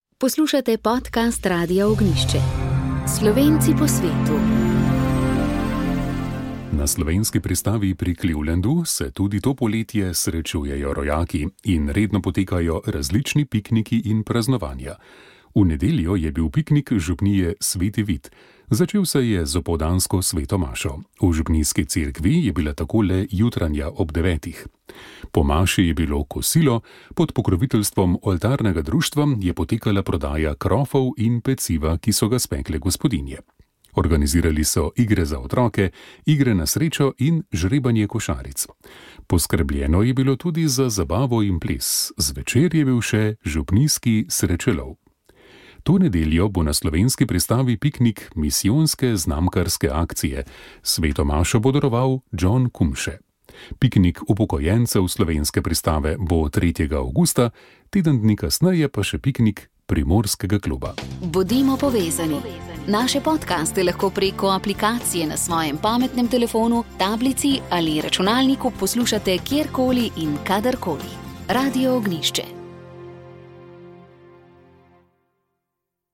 Radijska kateheza